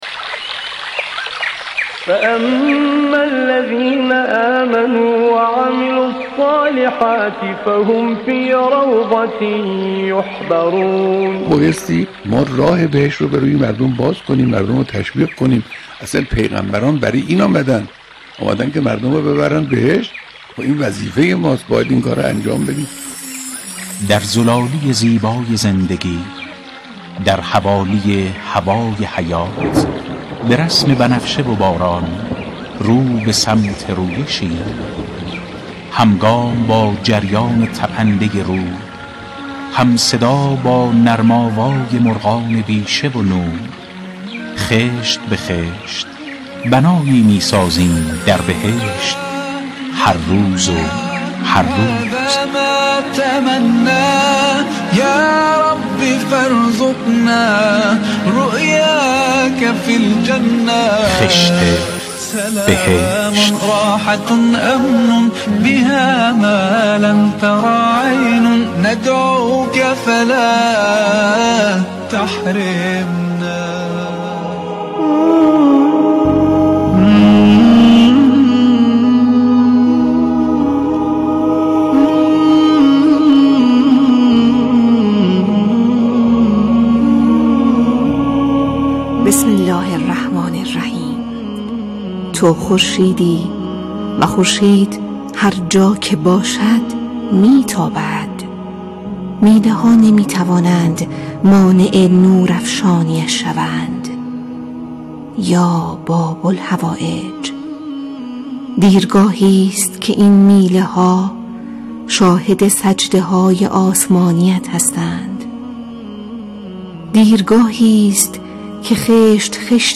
در هر برنامه «خشت بهشت» یك موضوع اصلی تعریف می‌شود كه در قالب بحث‌های كارشناسی، نمایش، گزارش و ... به آن پرداخته می‌شود و مخاطبان نیز از طریق پیامك و تلفن در آن مشاركت و همفكری می‌كنند.